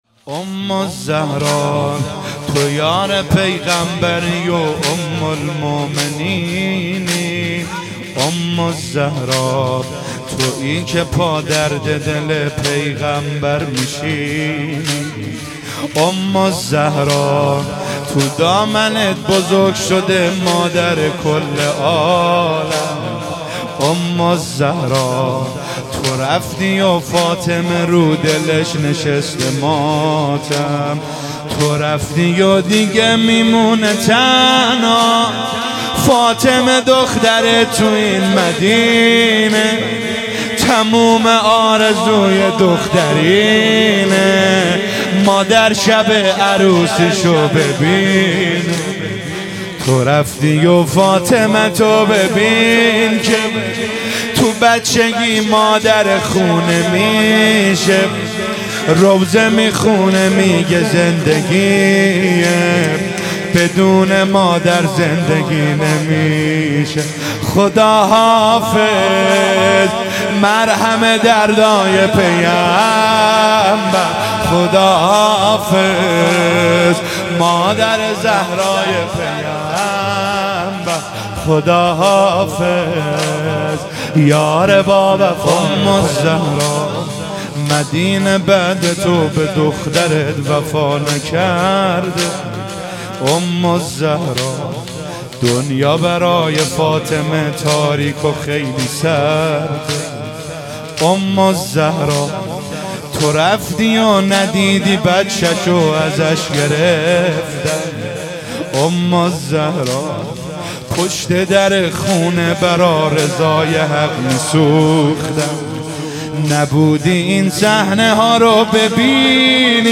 شهادت حضرت خدیجه سلام الله علیها | هیئت جنت العباس (ع) کاشان